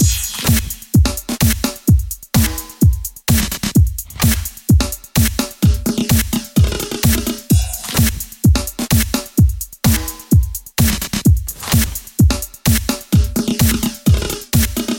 四轮驱动大鼓
标签： 128 bpm House Loops Drum Loops 2.52 MB wav Key : Unknown
声道立体声